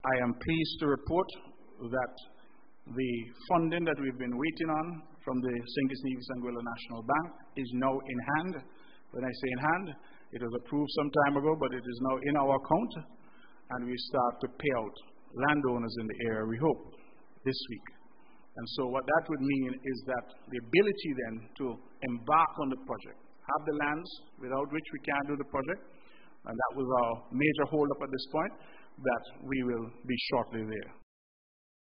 Premier Mark Brantley, on July 13th, 2025, gave this piece of information that would allow work on the Vance W. Amory International Airport to commence: